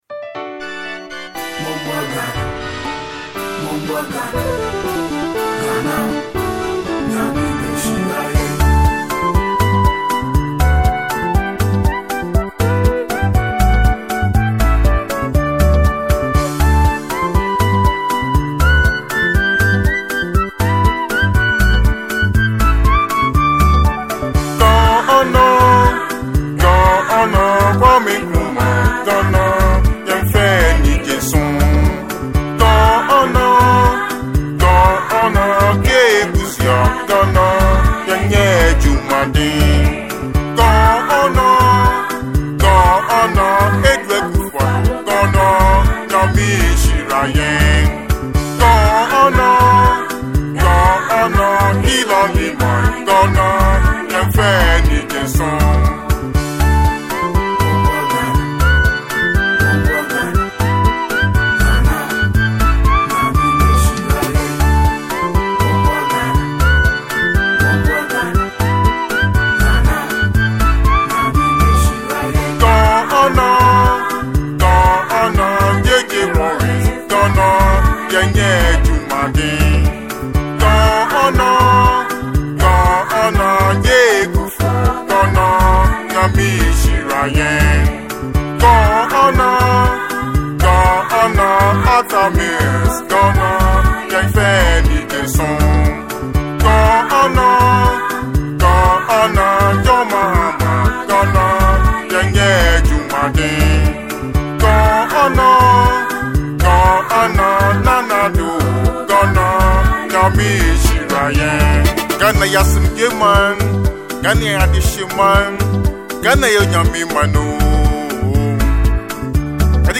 single
With his powerful lyrics and melodic tunes